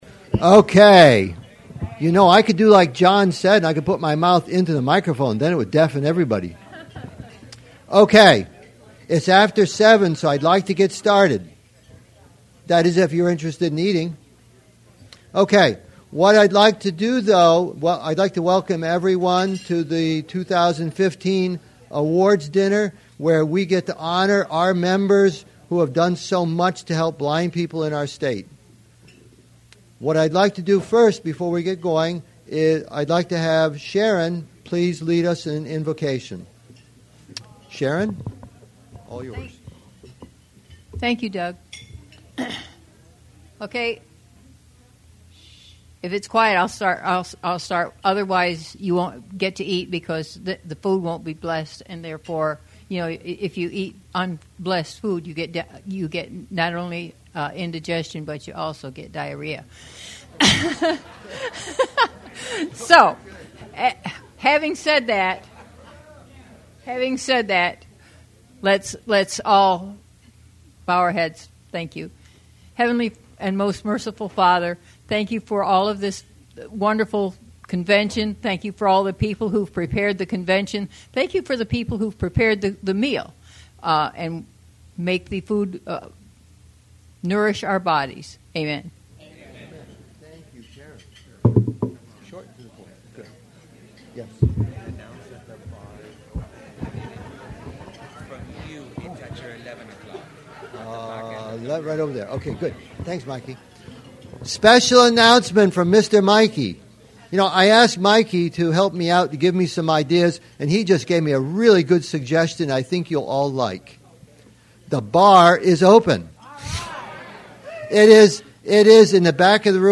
These are the archives for the 2015 Florida Council of the Blind Convention which was held in Orlando.
fcb2015-awards.mp3